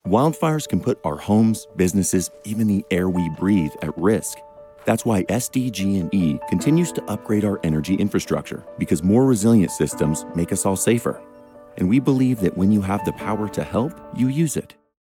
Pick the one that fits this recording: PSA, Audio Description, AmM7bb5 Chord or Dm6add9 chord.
PSA